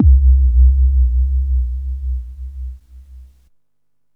Synth Impact 11.wav